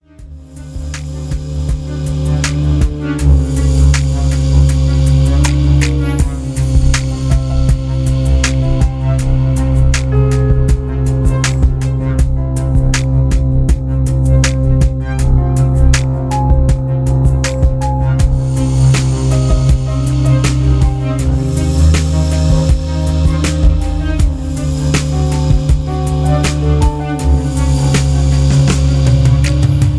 A trumpets cry.